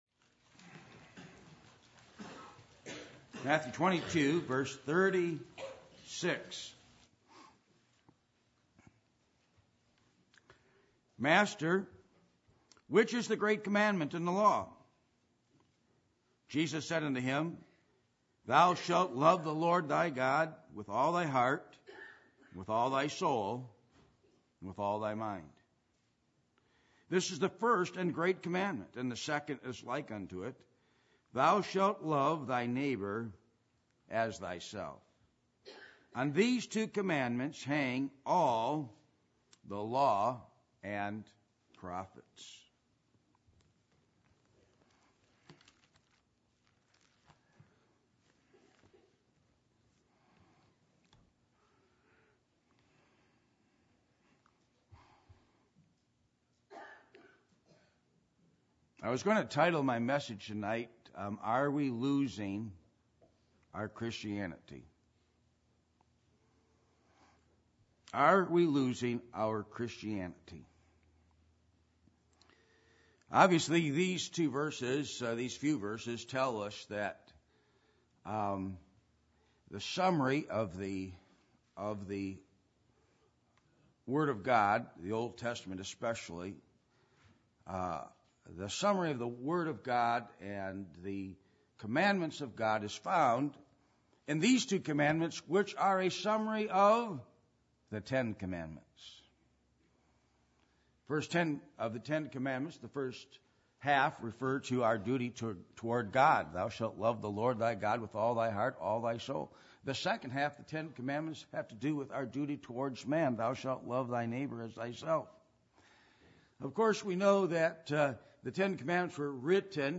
Matthew 22:38-40 Service Type: Sunday Evening %todo_render% « The Necessity Of The New Birth Selfishness